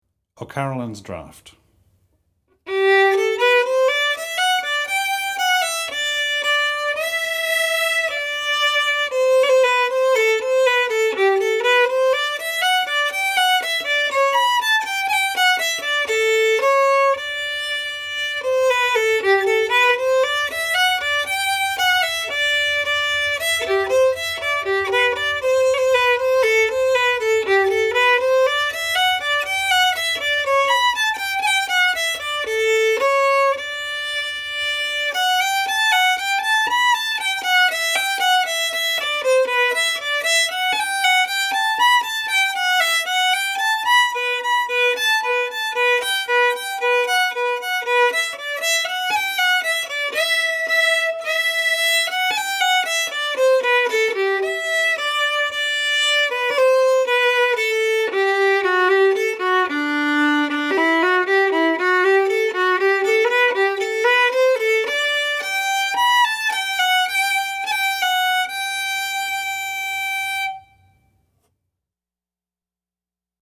DIGITAL SHEET MUSIC - FIDDLE SOLO
Fiddle Solo, Celtic/Irish, Air